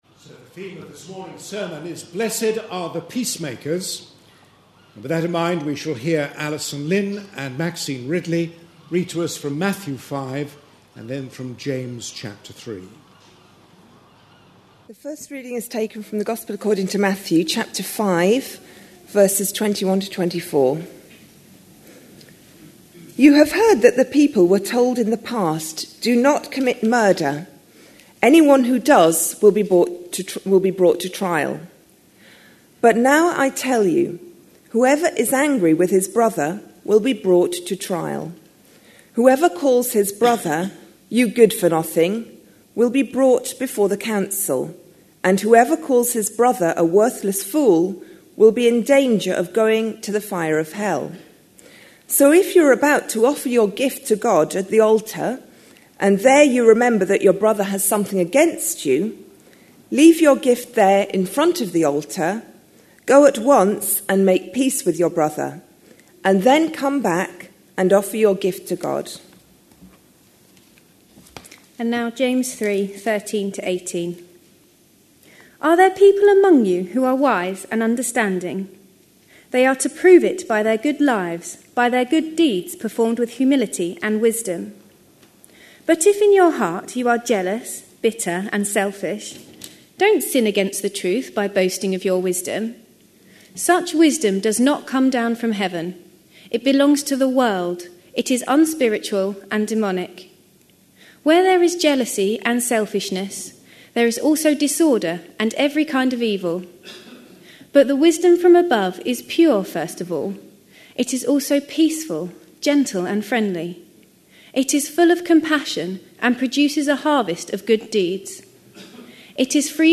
A sermon preached on 13th November, 2011, as part of our The Beatitudes. series.
This was Remembrance Sunday, and the sermon was followed by communion.